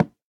Minecraft Version Minecraft Version 1.21.5 Latest Release | Latest Snapshot 1.21.5 / assets / minecraft / sounds / block / cherry_wood / break2.ogg Compare With Compare With Latest Release | Latest Snapshot